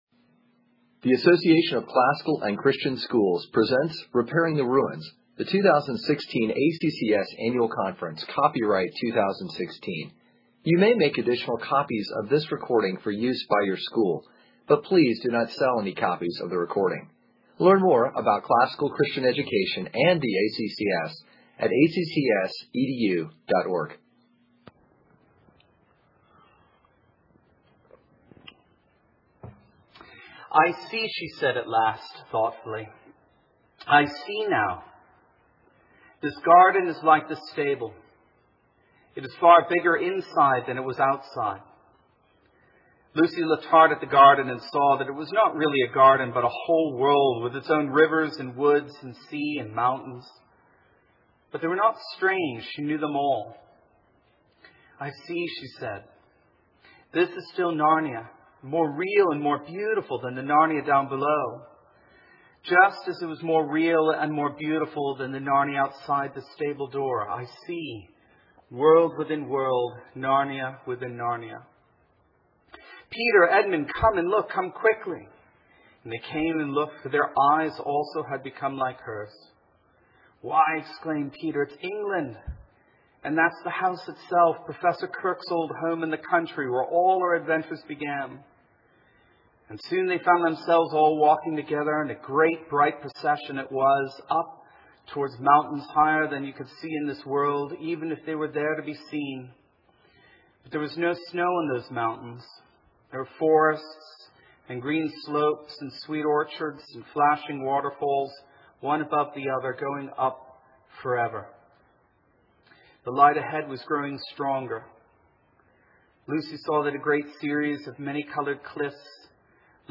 2016 Workshop Talk | 1:44:33 | All Grade Levels, General Classroom